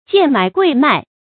賤買貴賣 注音： ㄐㄧㄢˋ ㄇㄞˇ ㄍㄨㄟˋ ㄇㄞˋ 讀音讀法： 意思解釋： 低價買進，高價售出。